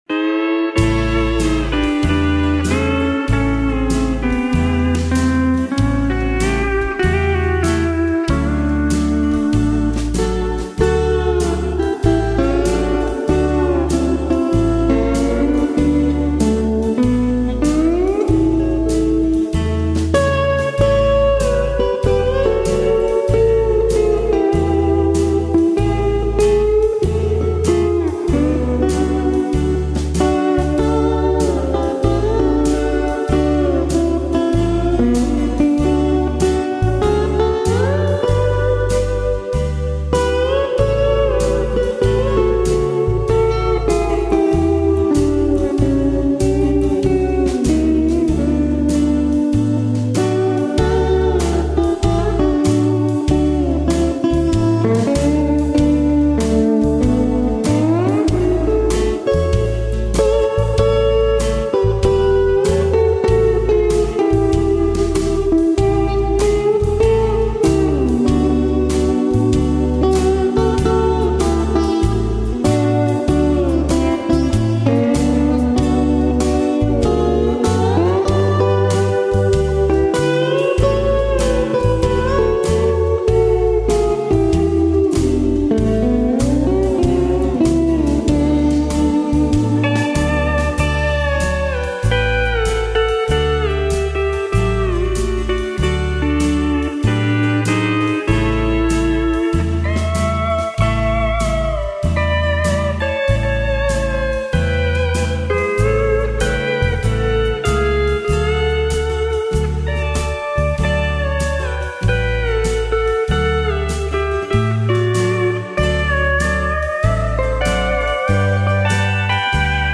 Fender Stringmaster Steel Guitar
Fender D8 Pedal Steel Guitar
Country